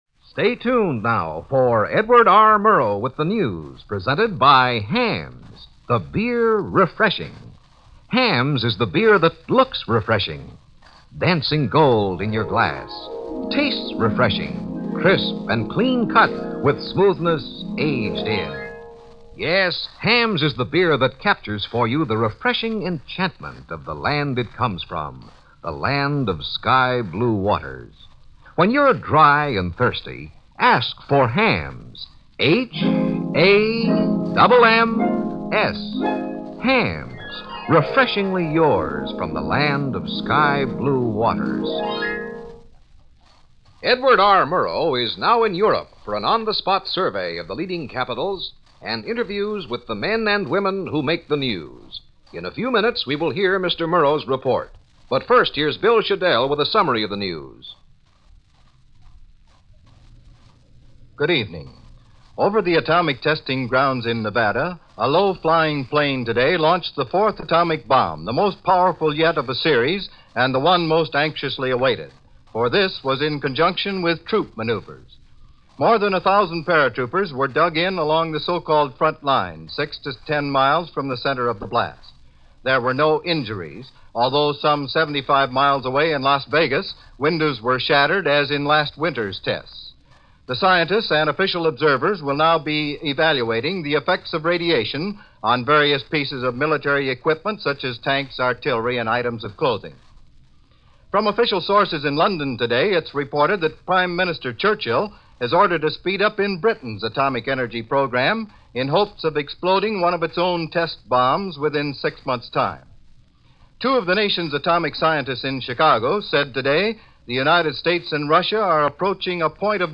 Atomic Testing - Things That Go Boom - News from this day in 1951 as reported by Don Hollenbeck, substituting for Edward R. Murrow